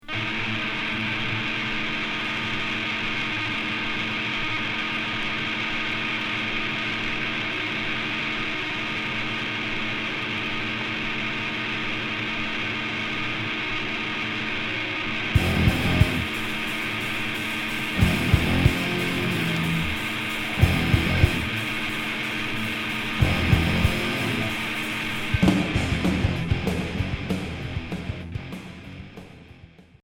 Hardcore Premier 45t